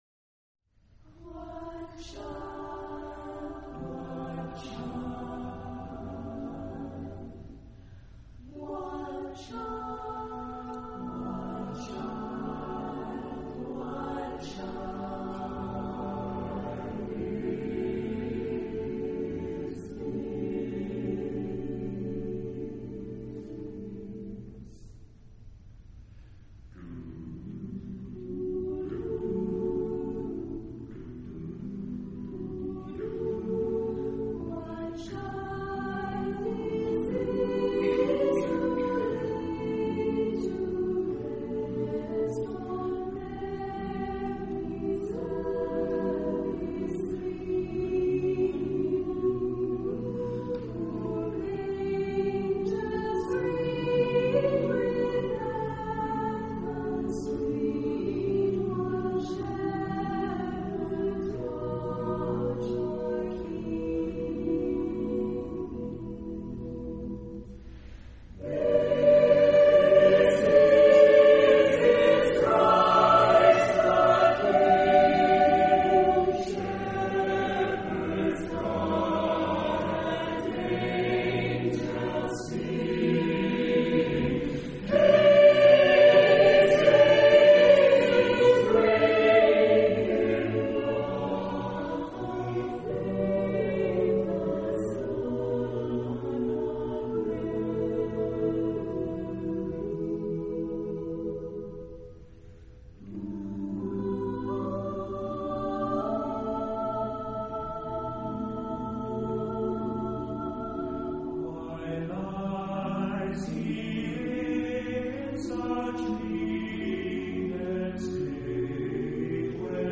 Charakter des Stückes: frei ; adagio
Chorgattung: SSAATTBB  (8 gemischter Chor Stimmen )